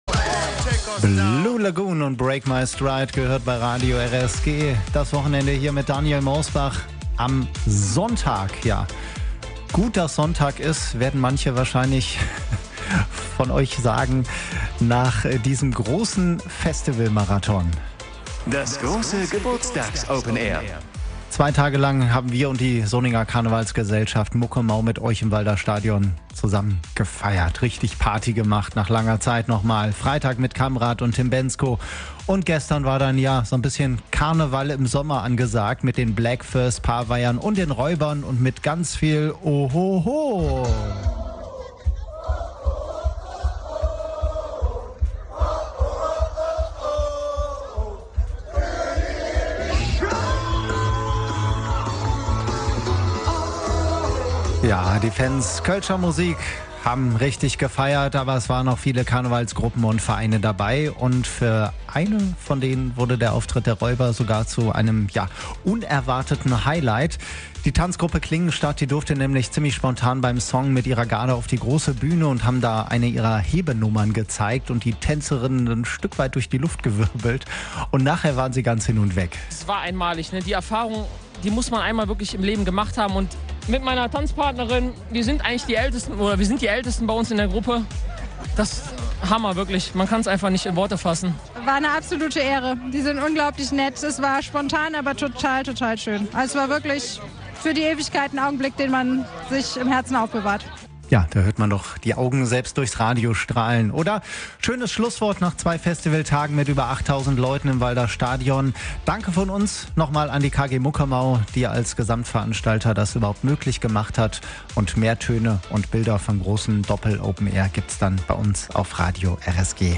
Karneval im Sommer: Die Kölner Kultbands Räuber, Paveier und Bläck Fööss sorgten beim Muckemau Open Air im Walder Stadion in Solingen für ausgelassene Stimmung bei hochsommerlichen Temperaturen.